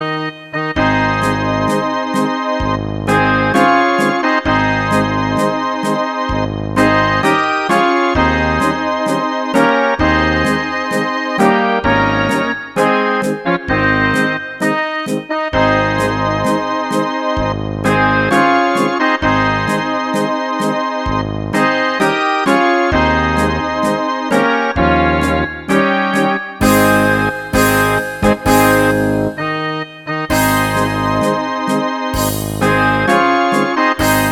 Rubrika: Národní, lidové, dechovka
- smuteční pochod